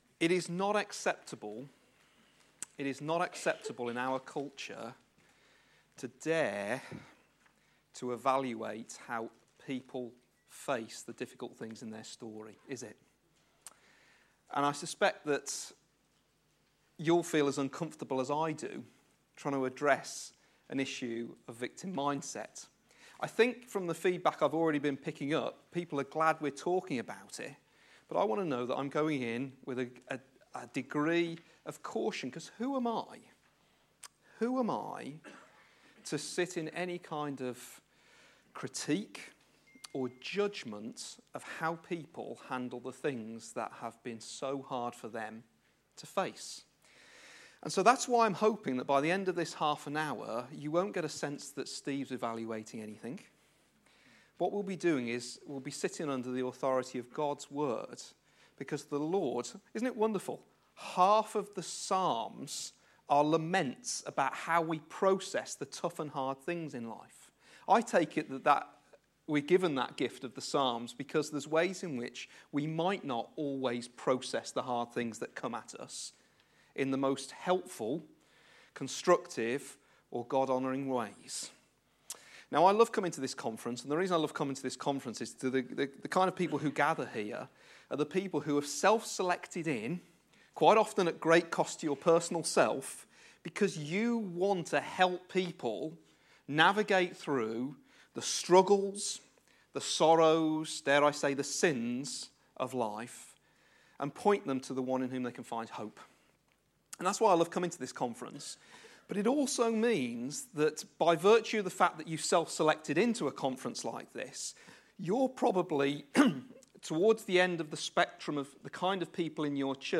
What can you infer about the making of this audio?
Anger: being cross, being ChristlikeResidential Conference, February 2018